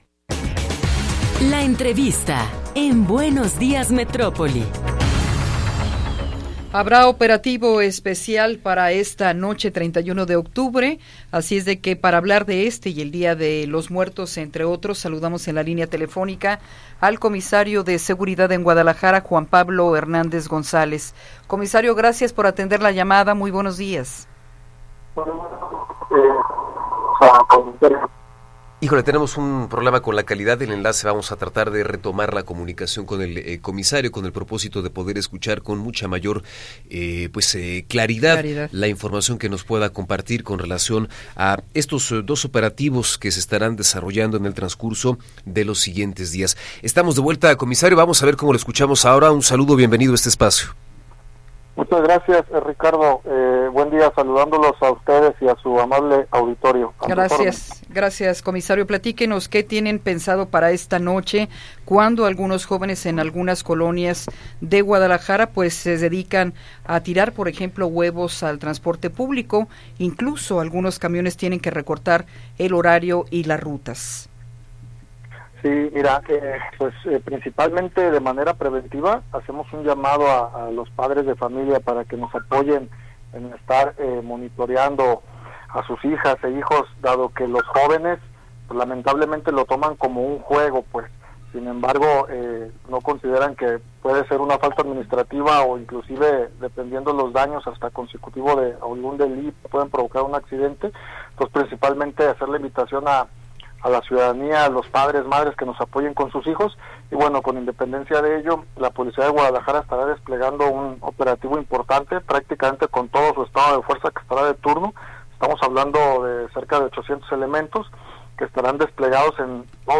Entrevista con Juan Pablo Hernández González